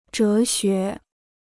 哲学 (zhé xué) Dicionário de Chinês gratuito